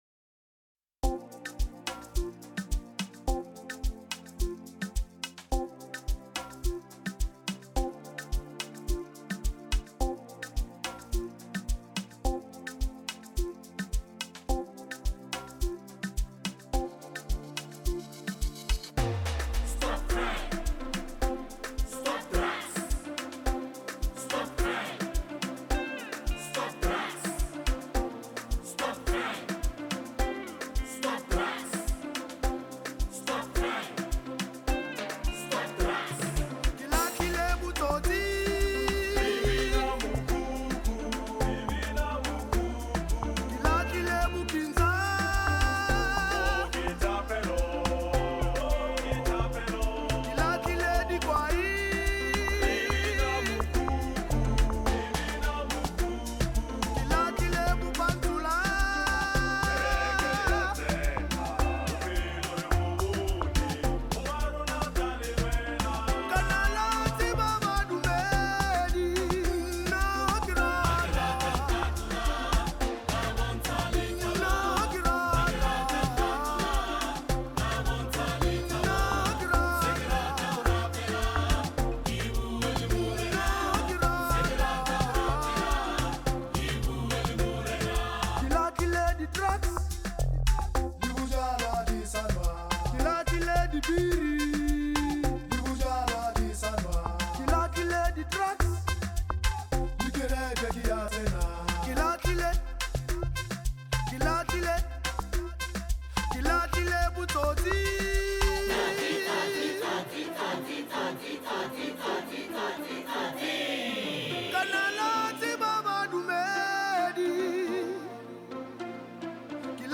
another south African Gospel singer
angelic voice